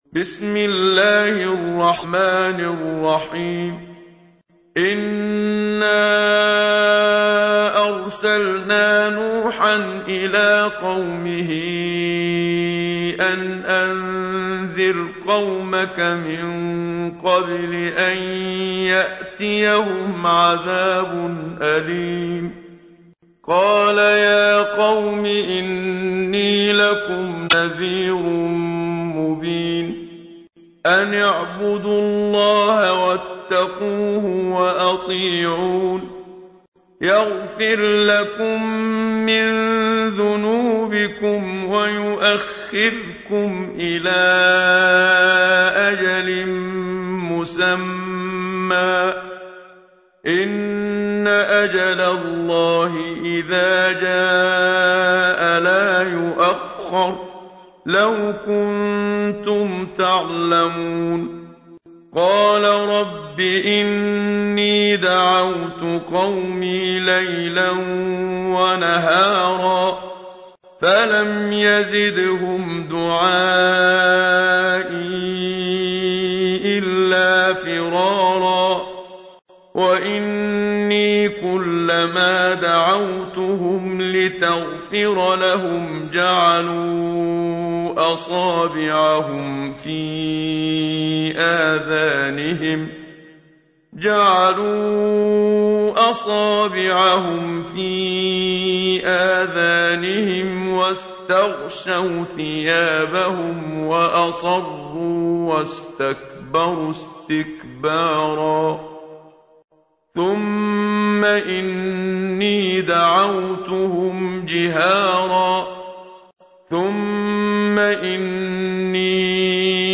تحميل : 71. سورة نوح / القارئ محمد صديق المنشاوي / القرآن الكريم / موقع يا حسين